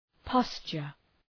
Προφορά
{‘pɒstʃər}